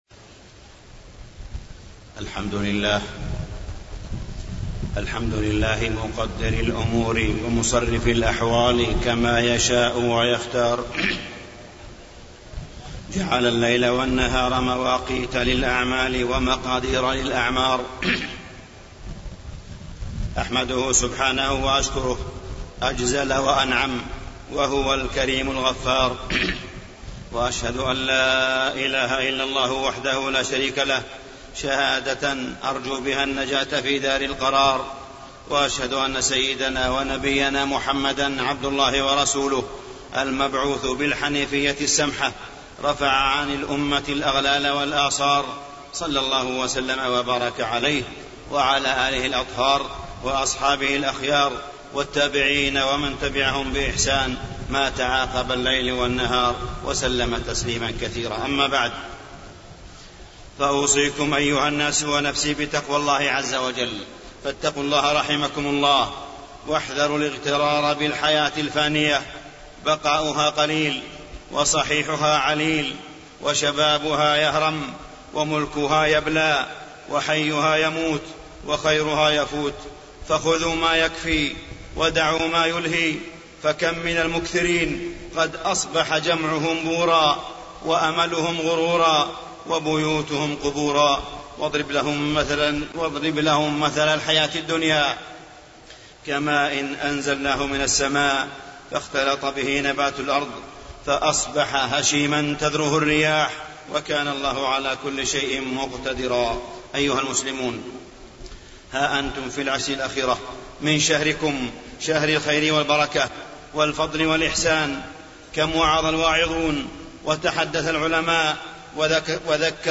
تاريخ النشر ٢٣ رمضان ١٤٢٨ هـ المكان: المسجد الحرام الشيخ: معالي الشيخ أ.د. صالح بن عبدالله بن حميد معالي الشيخ أ.د. صالح بن عبدالله بن حميد المحاسبة في رمضان